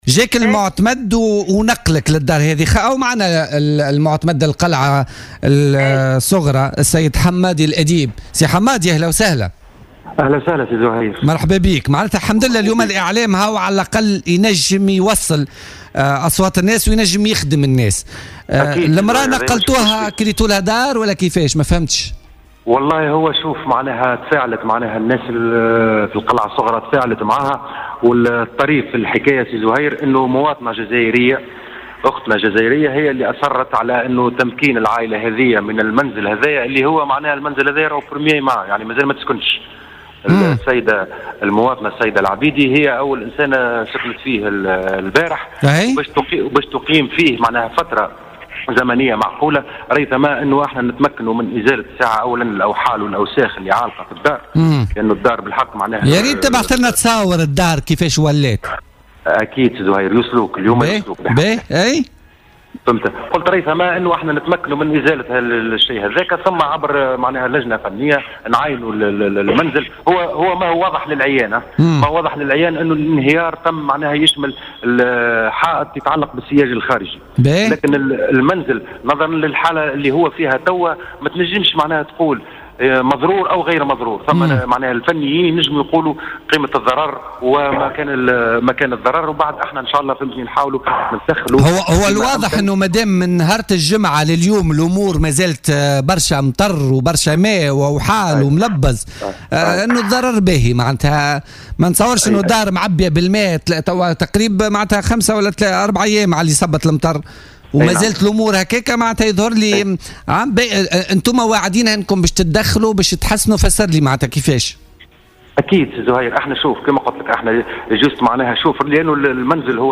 وقال في اتصال هاتفي بـ "الجوهرة اف أم" ببرنامج "بوليتيكا"